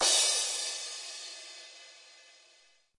电子碰撞（7）立体声
描述：数字撞镲与立体声效果
Tag: 碰撞 鼓数字